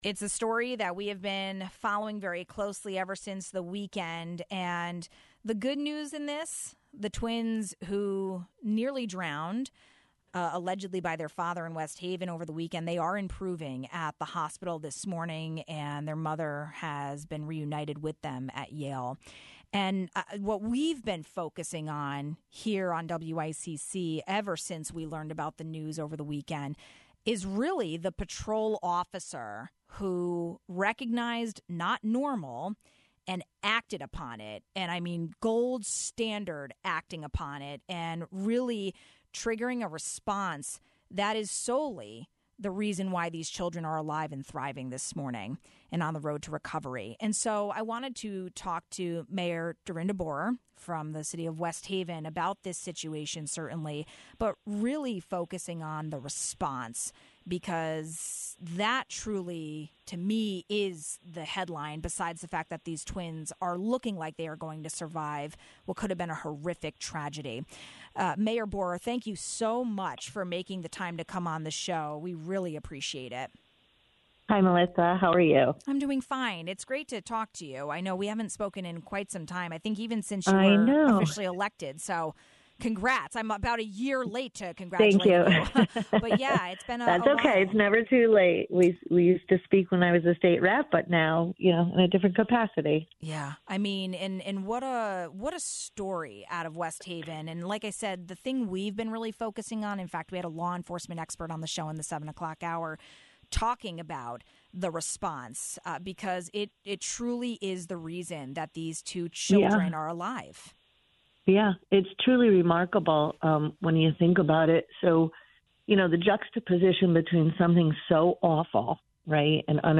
We spoke with West Haven Mayor Dorinda Borer about the attempted drowning crime that happened at a local beach. The mayor shared the latest about rescuers’ response time and the aftermath recovery.